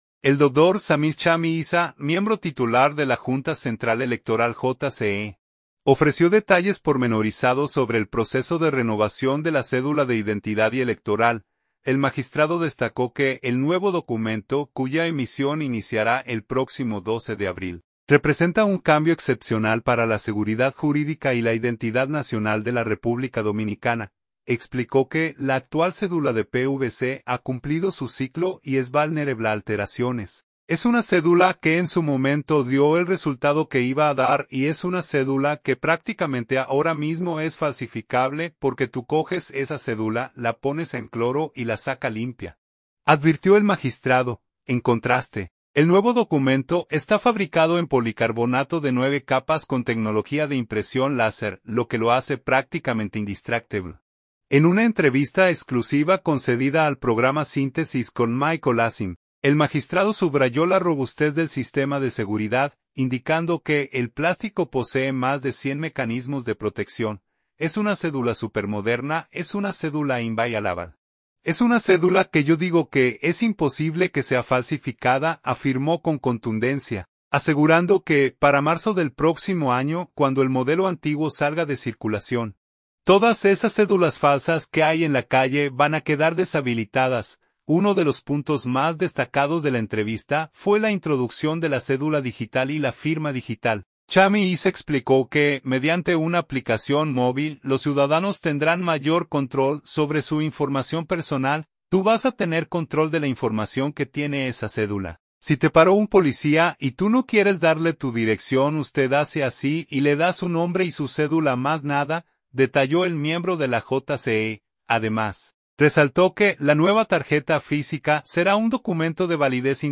Uno de los puntos más destacados de la entrevista fue la introducción de la cédula digital y la firma digital.